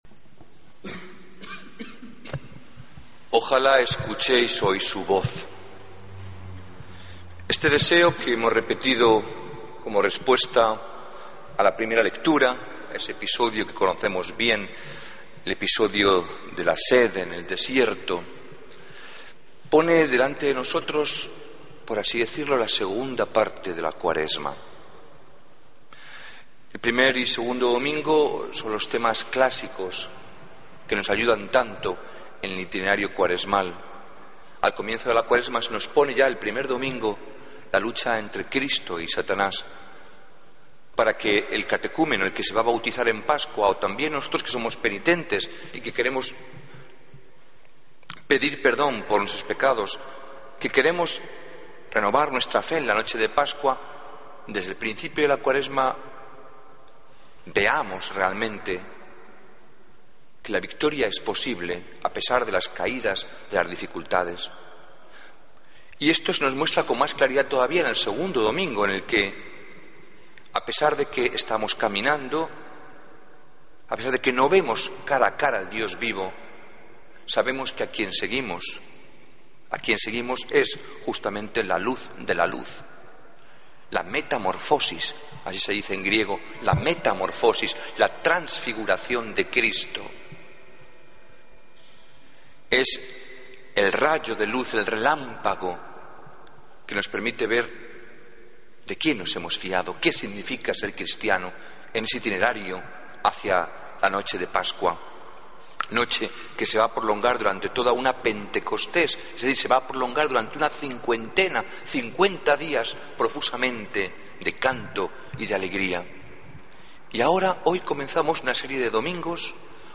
Homilía del Domingo 23 de Marzo de 2014